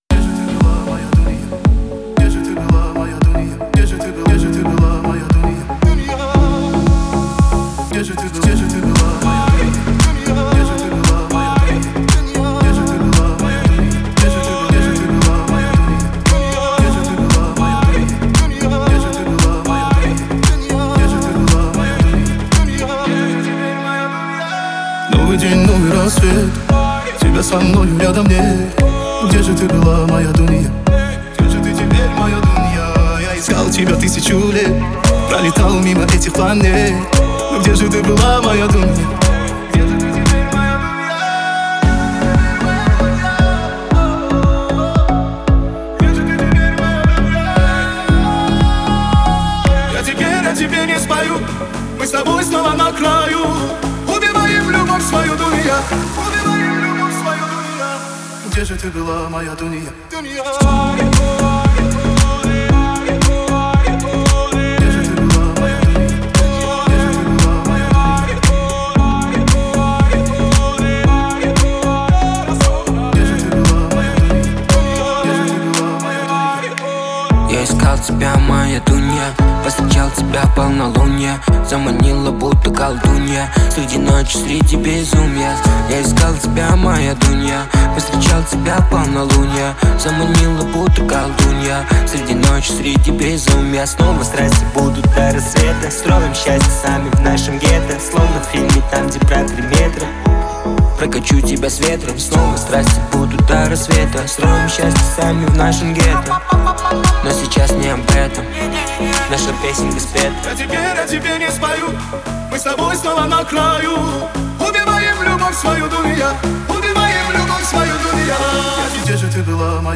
это завораживающий трек в жанре поп с элементами хип-хопа.